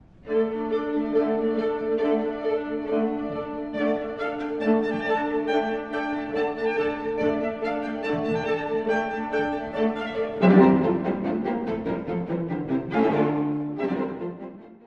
舞曲風の最終楽章です。
少し現世離れしたような五度の和音に導かれて、舞曲風の旋律が奏でられます。
この旋律はロンド風に繰り返されて、時には「祭り」の雰囲気にもなります。